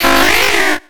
Cri de Kokiyas dans Pokémon X et Y.